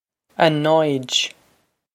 Pronunciation for how to say
a noij
This is an approximate phonetic pronunciation of the phrase.